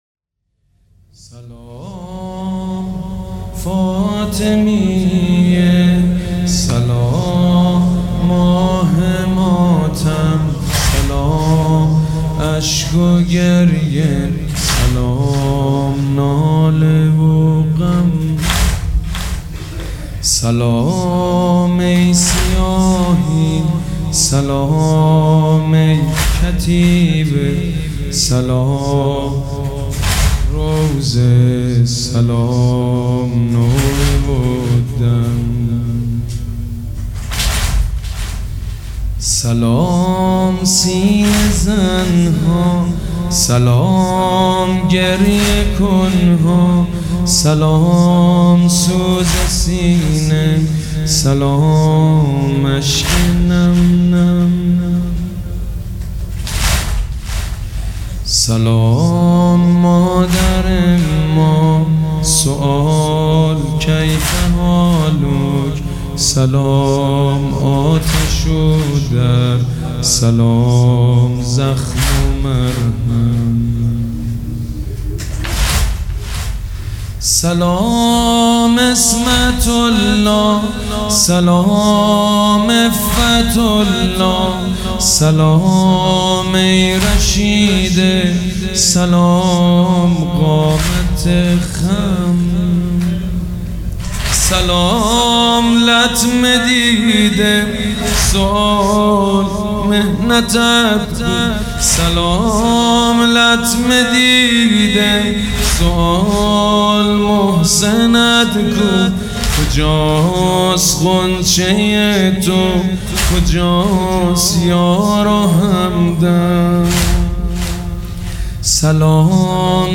سبک اثــر واحد
مداح حاج سید مجید بنی فاطمه
مراسم عزاداری شب اول